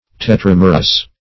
Tetramerous \Te*tram"er*ous\, a. [Tetra- + Gr.